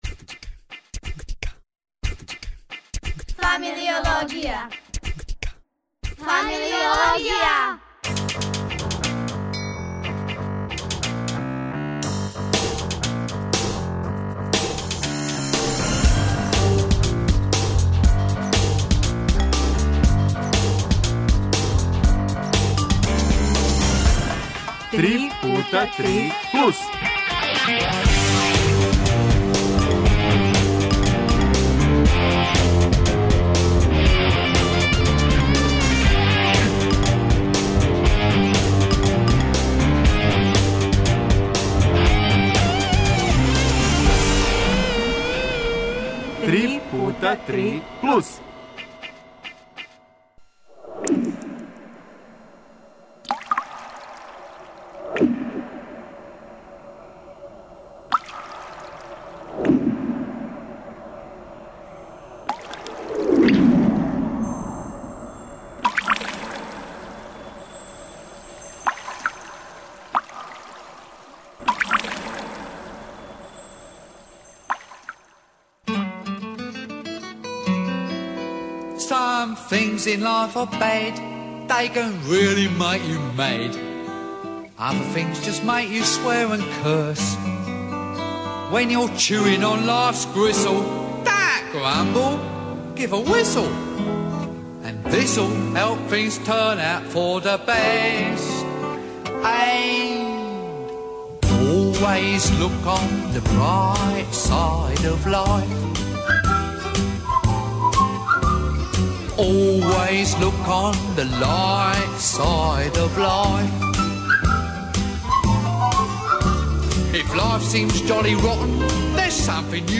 И, наравно, у госте нам долазе - деца.